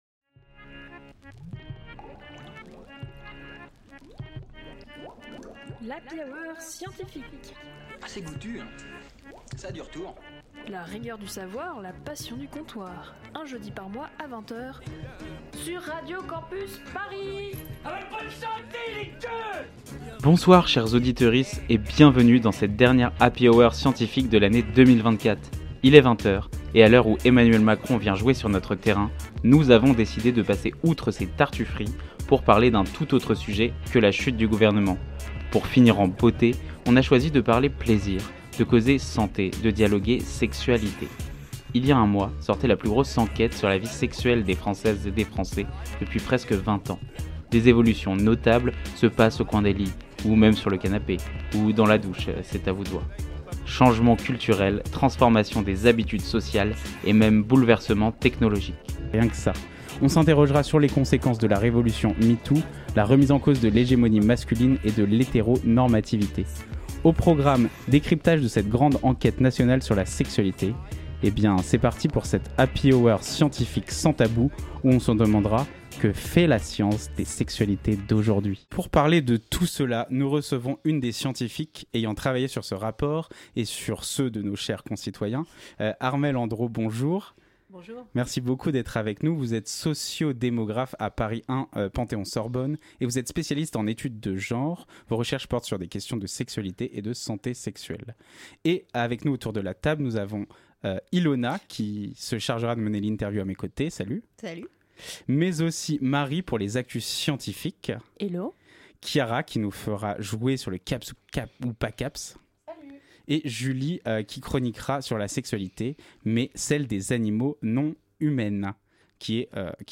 Partager Type Magazine Sciences jeudi 5 décembre 2024 Lire Pause Télécharger Pour finir en beauté cette année 2024, à l'HHS, on a choisi de parler plaisir, de causer santé, de dialoguer SEX-UA-LI-TÉ.